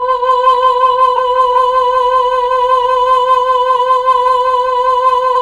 VOX_Chb Fm B_5-L.wav